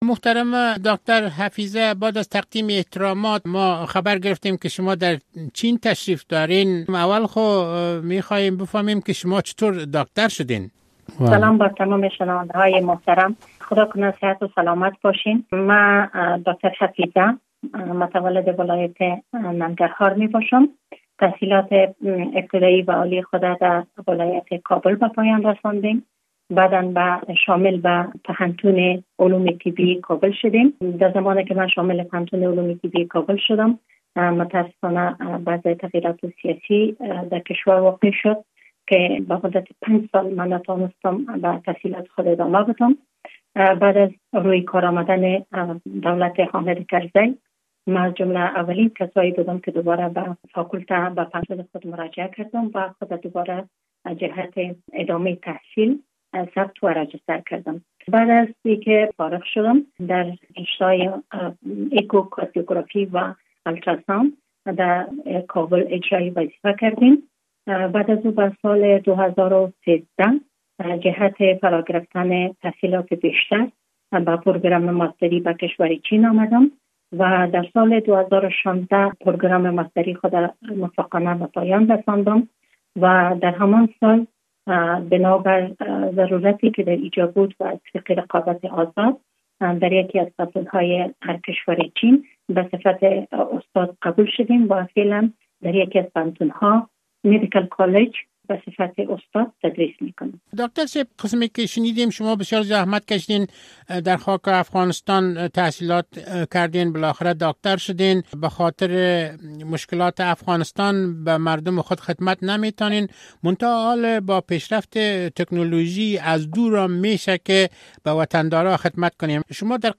شما می‌توانید قسمت اول مصاحبه را در اینجا بشنوید و اگر سوال خاص از ایشان دارید، می‌توانید در بخش نظریات این سایت بنویسید و یا در تلفون اتومات رادیو آزادی پیام ثبت نماید.